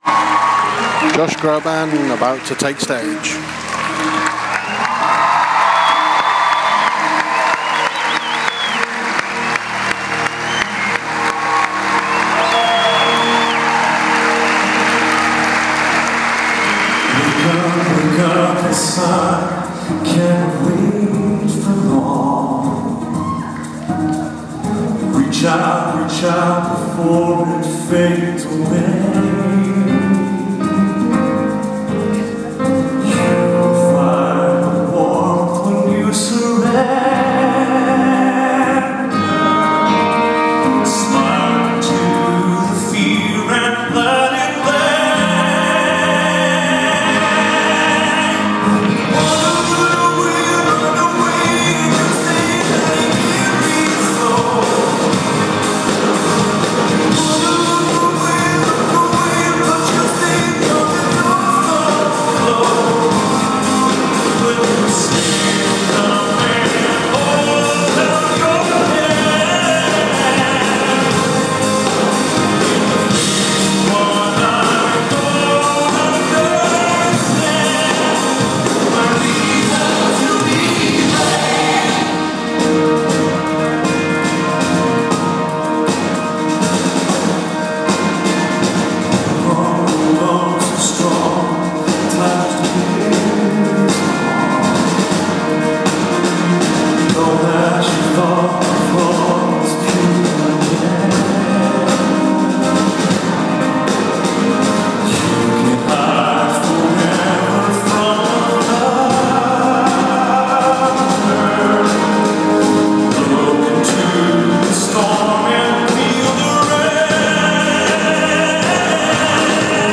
Concert